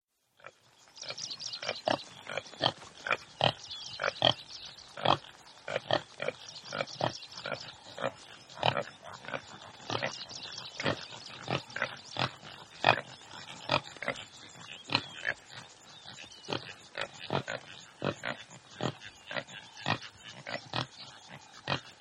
Записи сделаны в дикой природе и передают атмосферу саванны.
Стадо Джимела